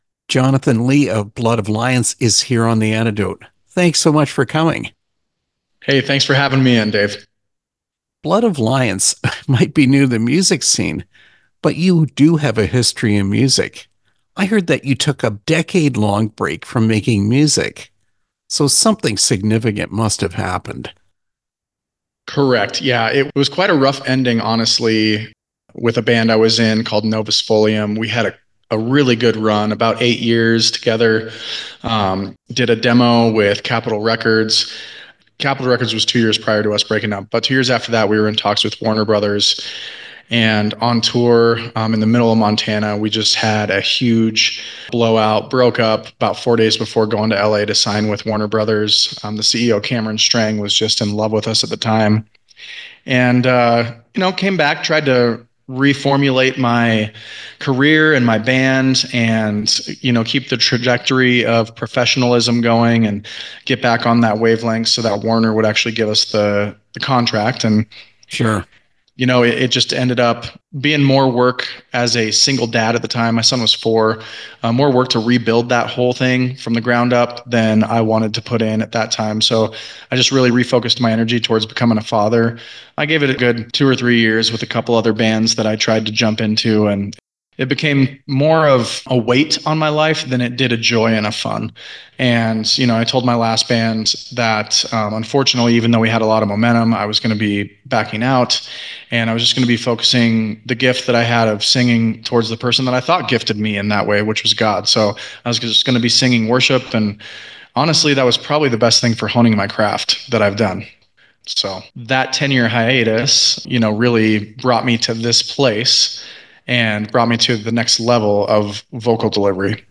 Interview with Blood of Lions